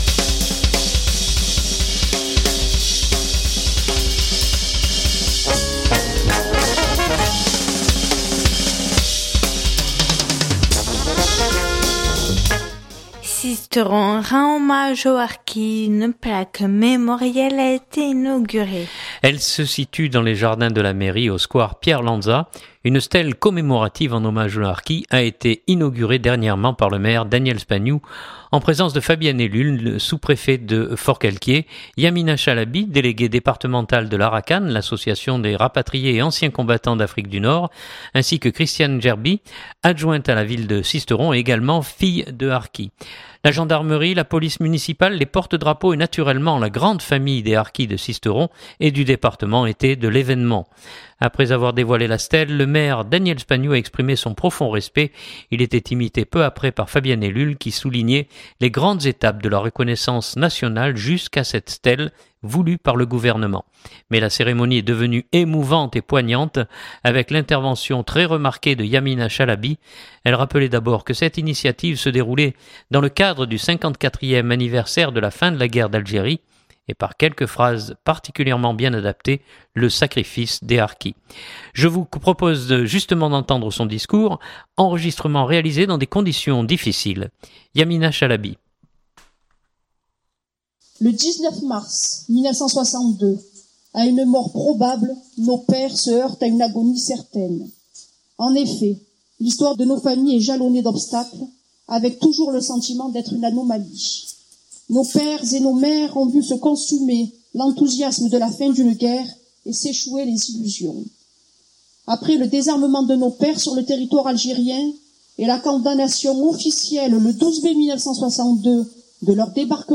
Enregistrement réalisé dans des conditions difficiles.